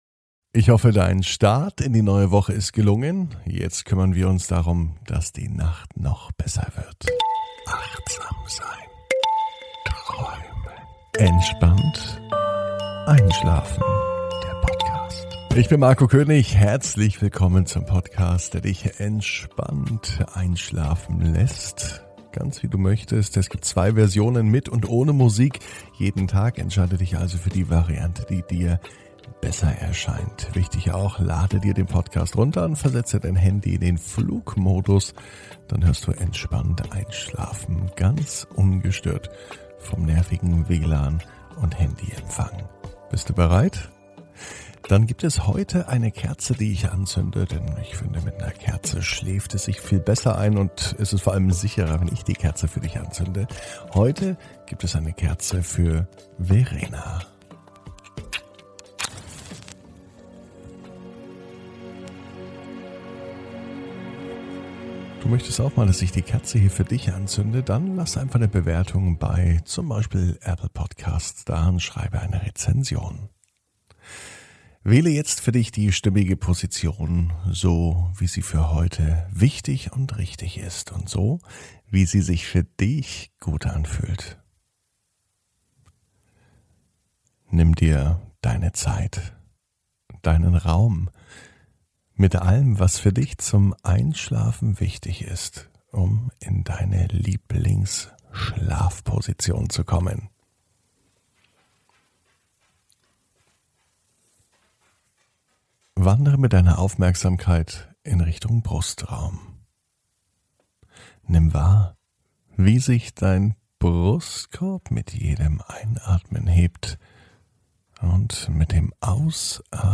(ohne Musik) Entspannt einschlafen am Montag, 07.06.21 ~ Entspannt einschlafen - Meditation & Achtsamkeit für die Nacht Podcast